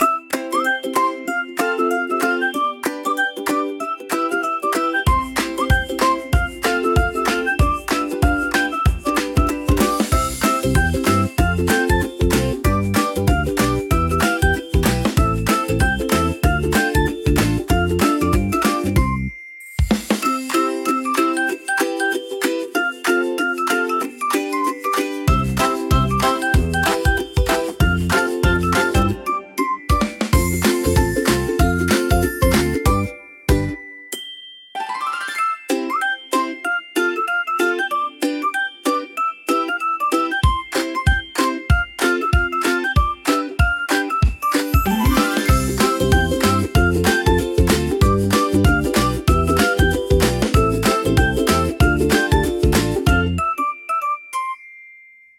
Upbeat Kids Melody